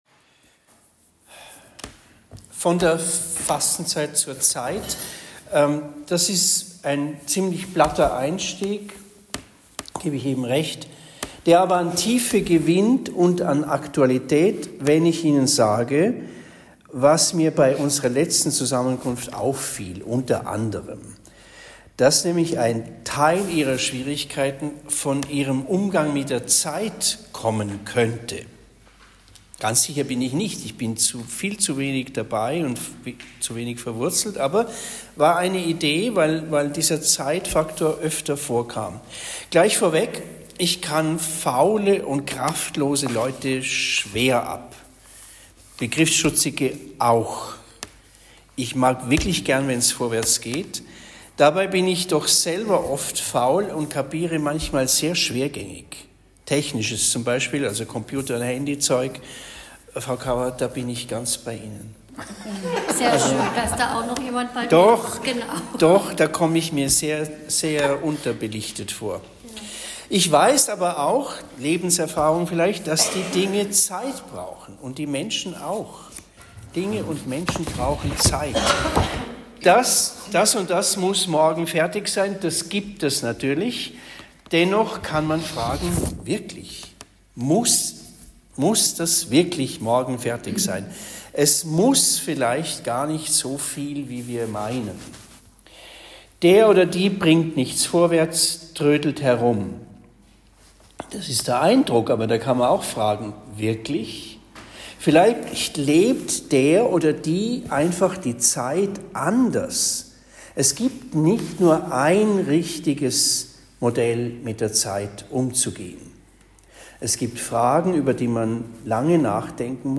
Vorstandssitzung der Malteser-Gliederung Wertheim am 26. Februar 2024